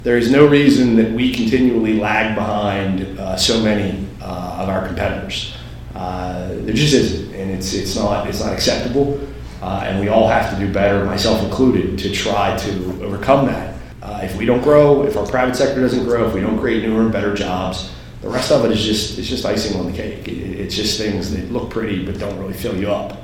At the annual legislative breakfast sponsored by the Allegany County Chamber of Commerce, the focus was the state’s budget deficit and how it will affect the state’s three westernmost counties.